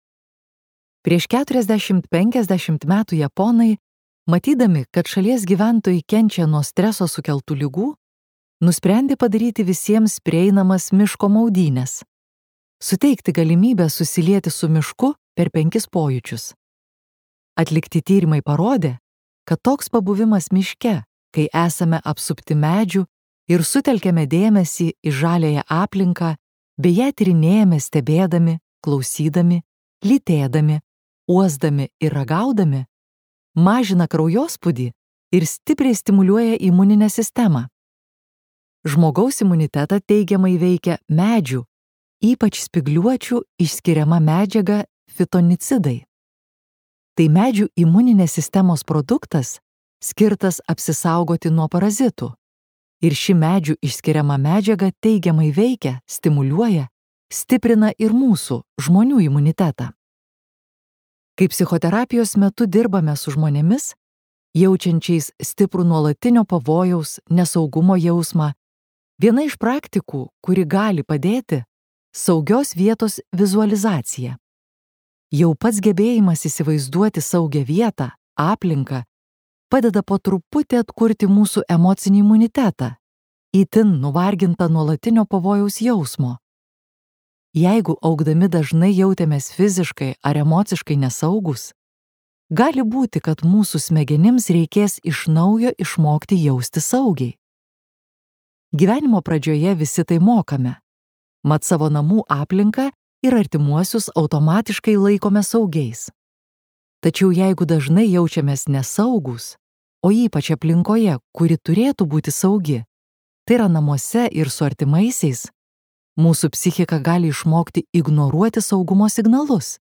Skaityti ištrauką play 00:00 Share on Facebook Share on Twitter Share on Pinterest Audio Gamtos terapija.